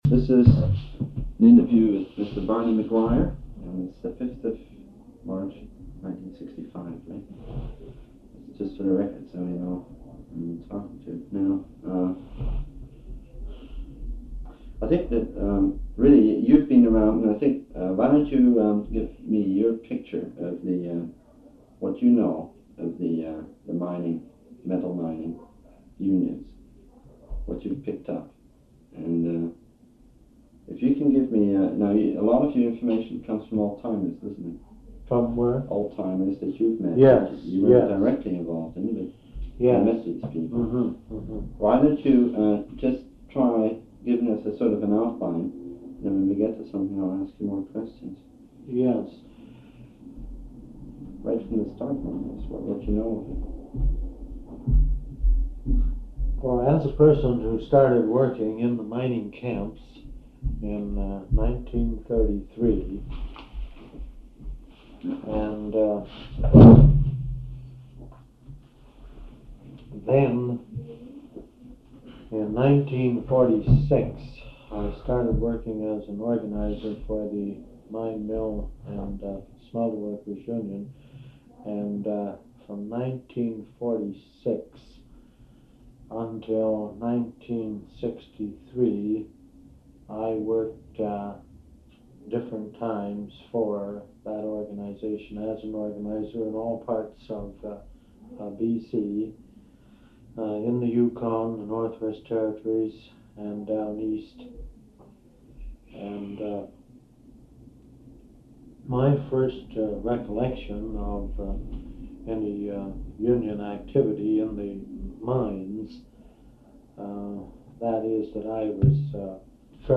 This interview covers decades of B.C. mining union history and union organizing.